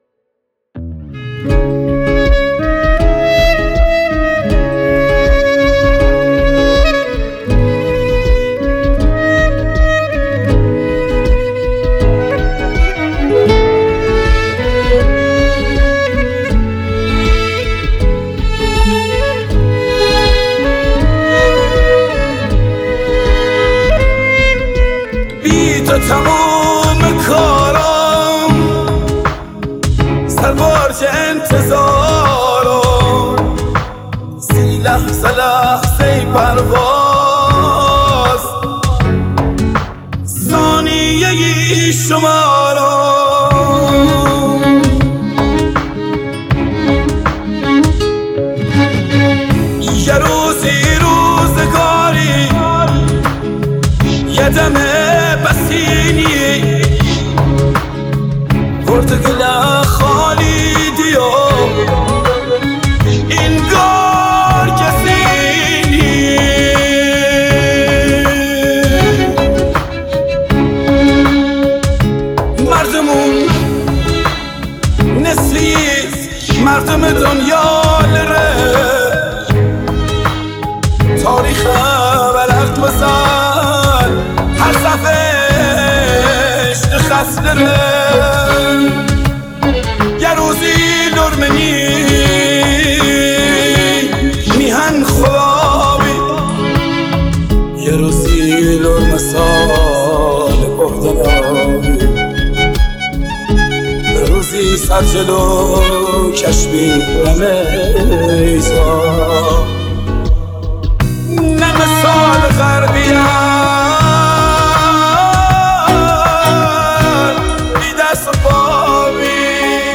دانلود آهنگ لری
موسیقی اصیل بختیاری موسیقی اصیل لری _ بختیاری